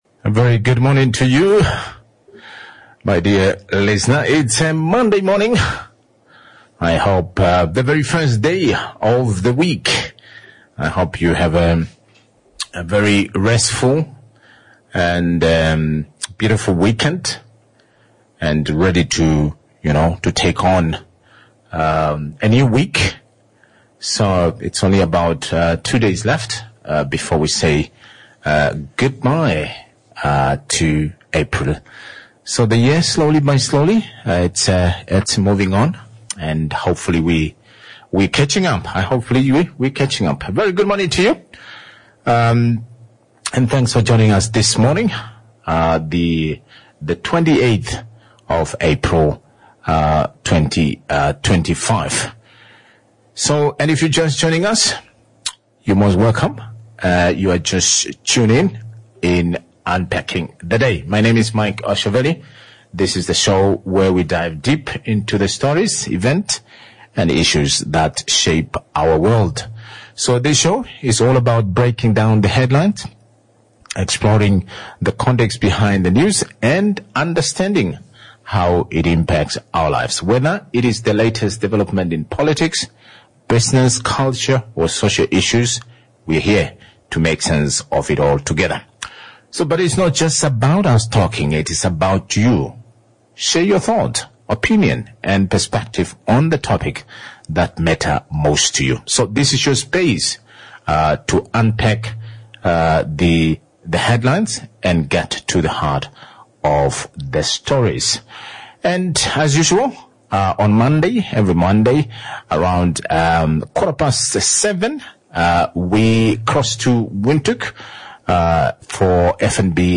No tuition, no registration fees from 2026, interviewed